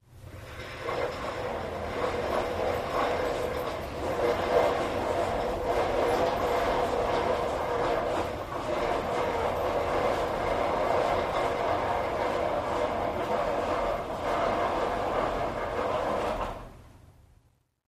Stage Curtain: Pulley Sliding Open Close.